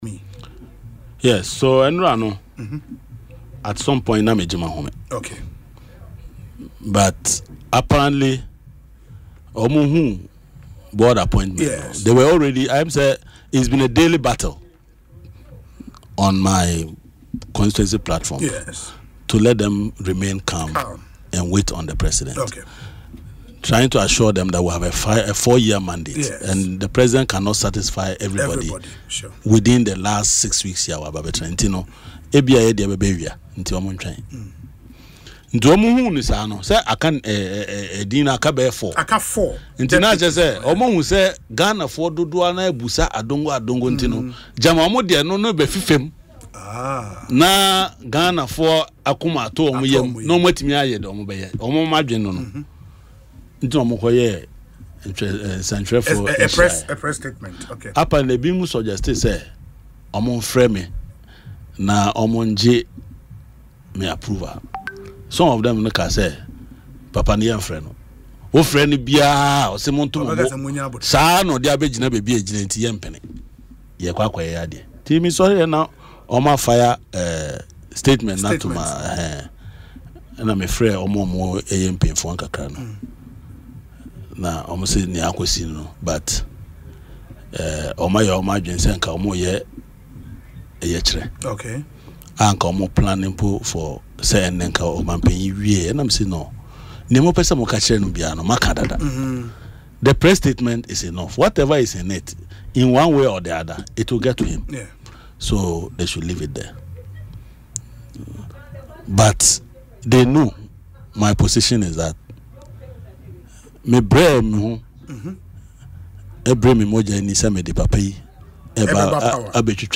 In an interview on Asempa FM’s Ekosii Sen show, Mr. Adongo revealed that his constituents, too, were equally disappointed by the omission.